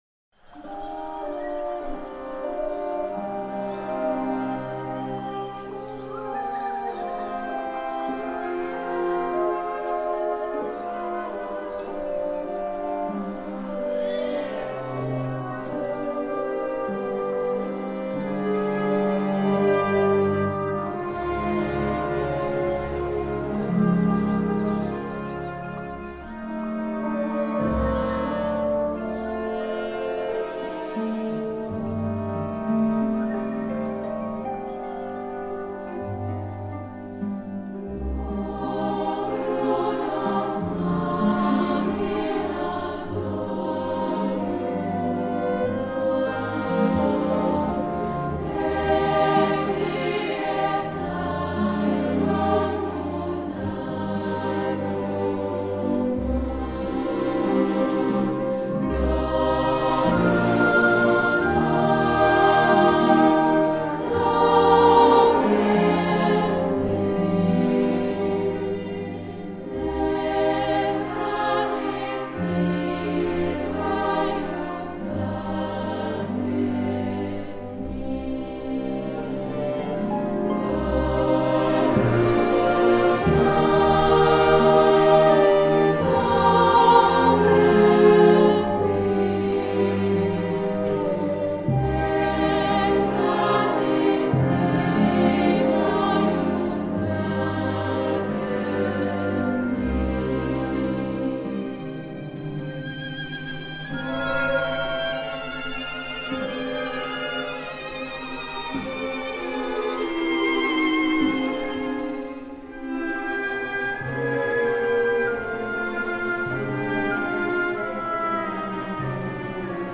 “Dobrù noc” melodia popolare di Moravia – Fabio Arnaboldi
Orchestrata e trascritta per coro femminile o di voci bianche e orchestra
Orchestra della Svizzera Italiana